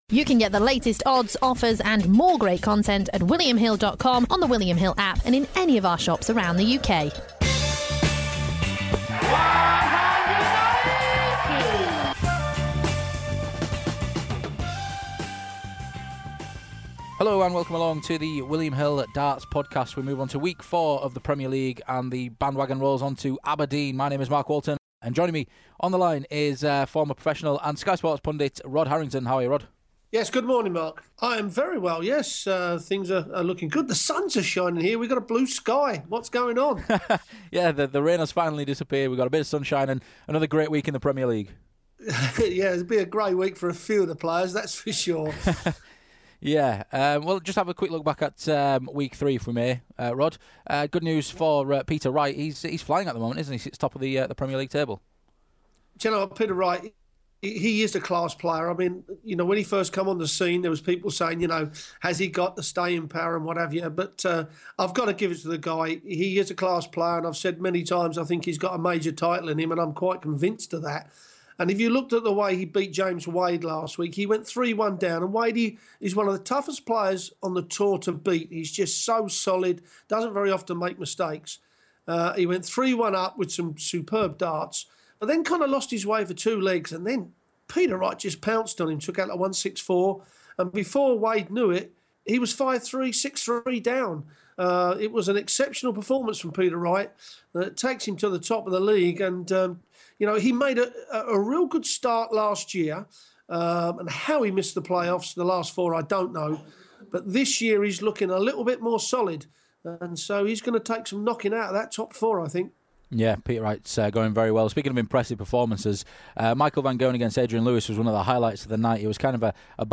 Former professional darts player, Rod Harrington,